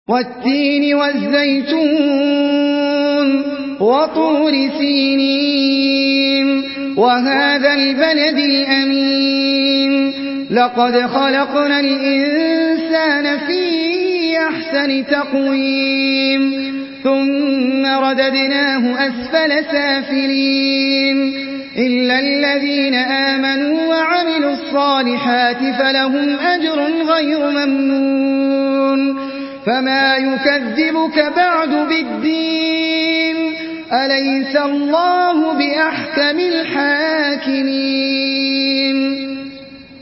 Surah At-Tin MP3 by Ahmed Al Ajmi in Hafs An Asim narration.
Murattal Hafs An Asim